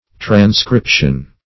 Transcription \Tran*scrip"tion\ (tr[a^]n*skr[i^]p"sh[u^]n), n.